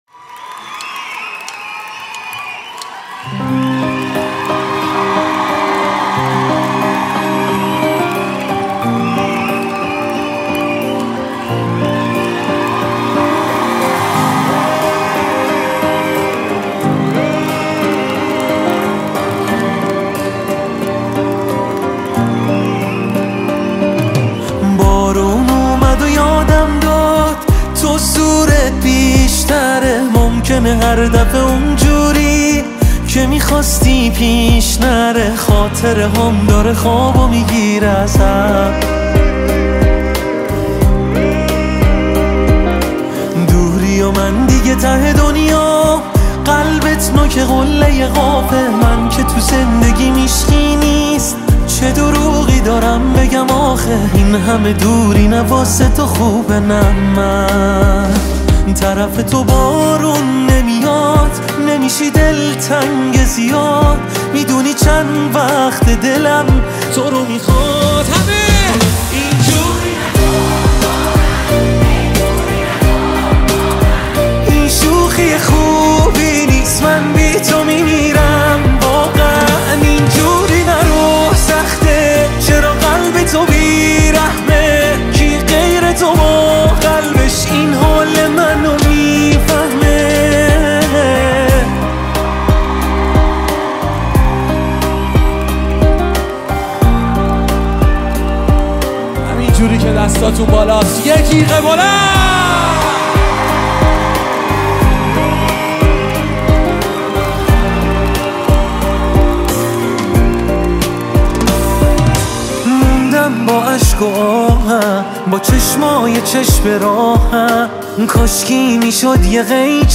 Live In Concert